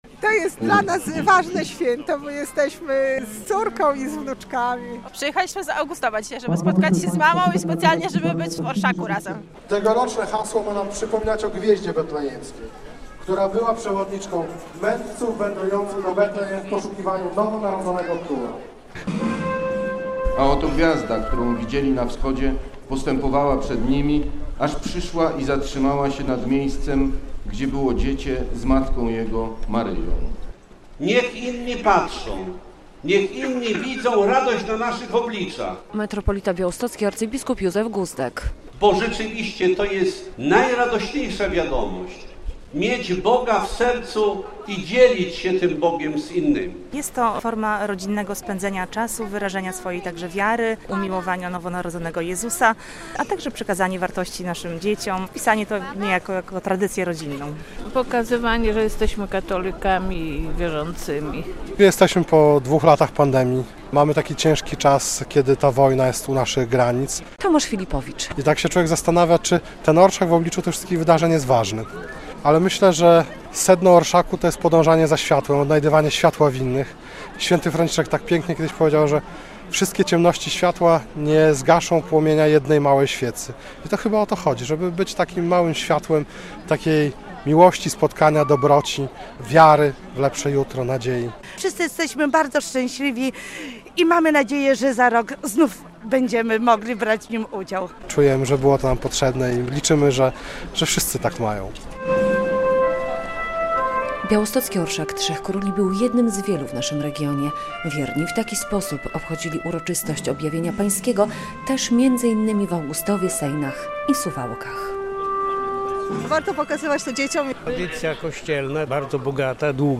Orszaki Trzech Króli przeszły ulicami podlaskich miast - relacja